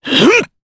Clause_ice-Vox_Casting3_jp.wav